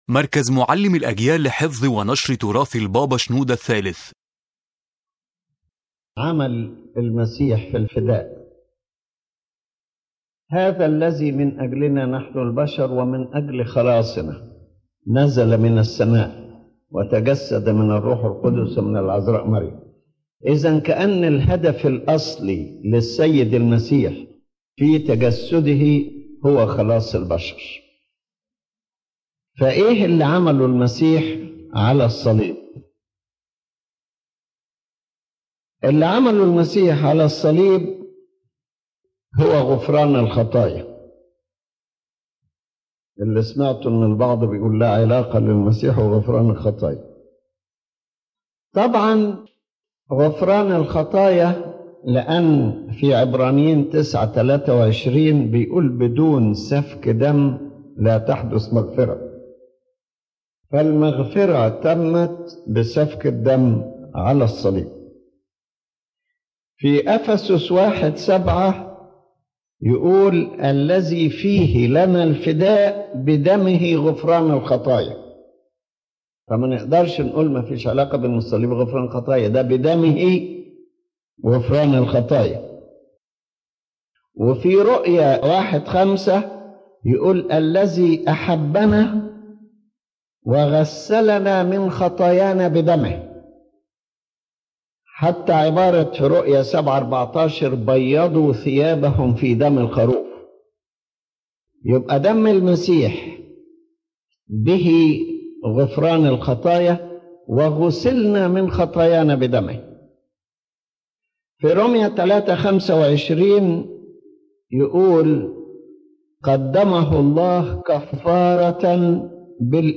This lecture explains the core of the Christian faith as proclaimed by the Coptic Orthodox Church, emphasizing that redemption is the primary purpose of the Incarnation of Christ, and that the Cross stands at the center of divine salvation for humanity.